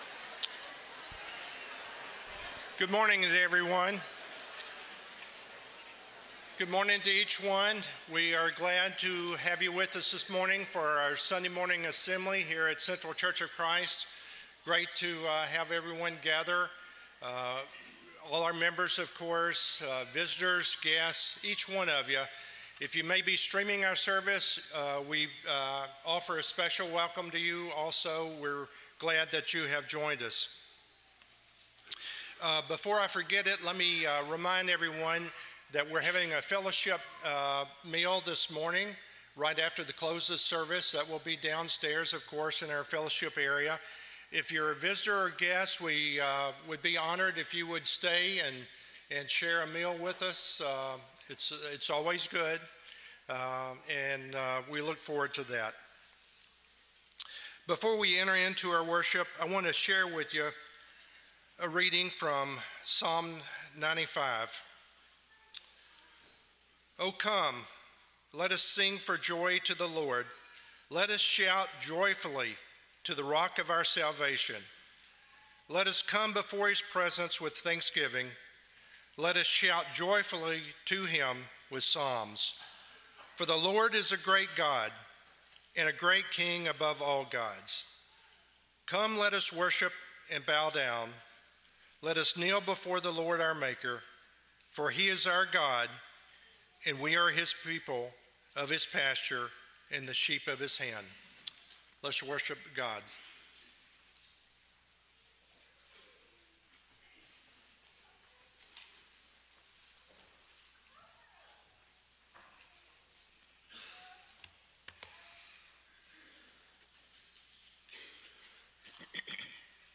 (Hebrews 12:14, English Standard Version) Series: Sunday AM Service